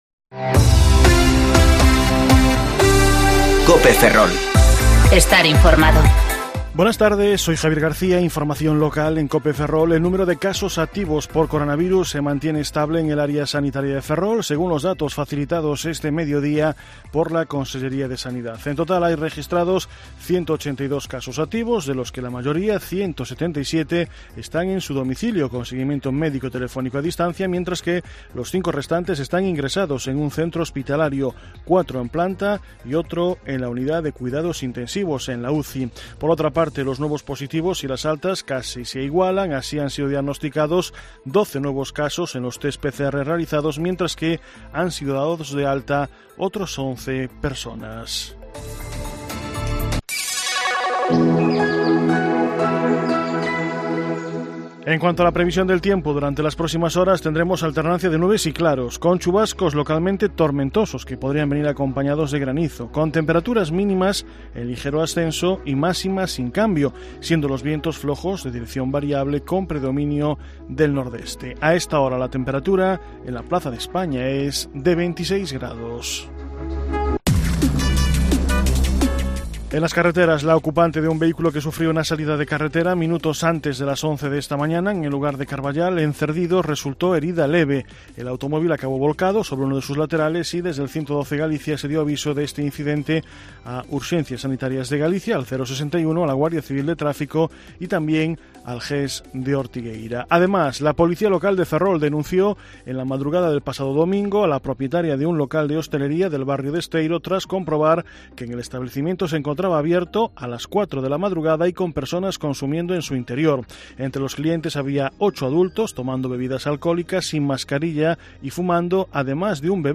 Informativo Mediodía COPE Ferrol 17/9/2020 (De 14,20 a 14,30 horas)